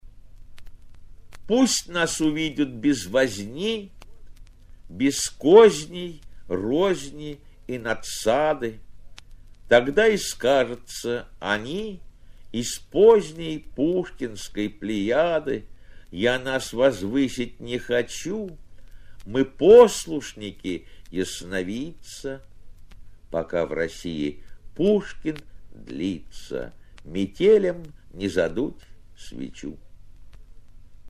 2. «Давид Самойлов – Пусть нас увидят без возни (читает автор)» /
david-samojlov-pust-nas-uvidyat-bez-vozni-chitaet-avtor